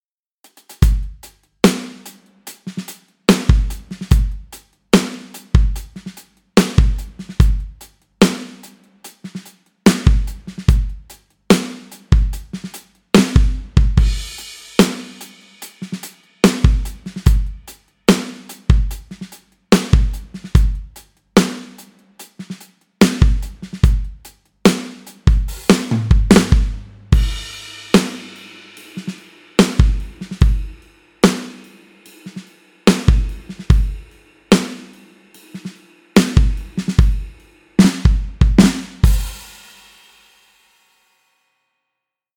キングオブポップも使用した最高のスタジオで収録されたLeedy & Ludwigドラムキット
UNITED POPは、ヴィンテージのLeedyとLudwigキットで構成され、スネアは非常にレアなLudwig nickel over brass（NOB）スネア、Trumpスネア（日本製）を収録しています。エクストラにはカスタネットやタンバリンが用意されています。
• Ocean Wayスタジオ（現UnitedRecordingsスタジオ）で収録
• Zildjian Vintage K ハイハット
• エクストラにクラベス / カスタネット/ ウッド ・プラスチックブロック/ タンバリン / ビブラスラップ / リム
Kick：22×14″ Leedy & Ludwig